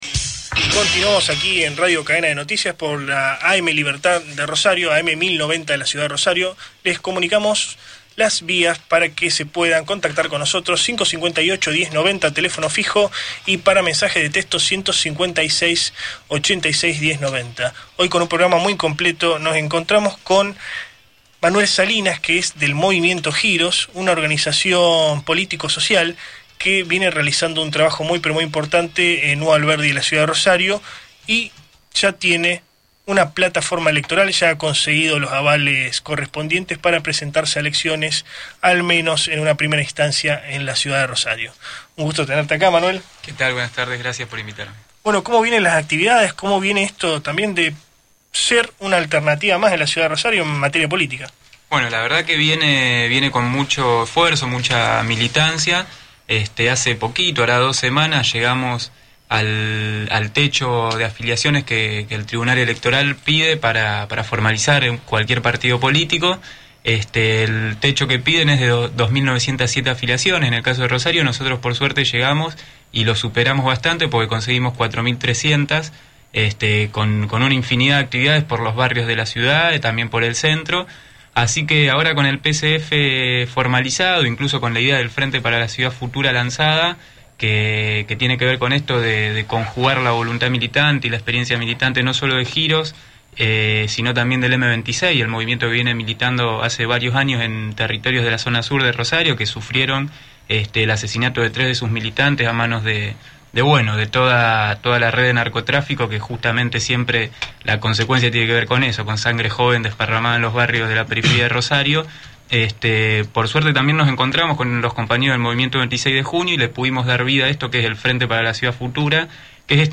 MOVIMIENTO GIROS AUDIO ENTREVISTA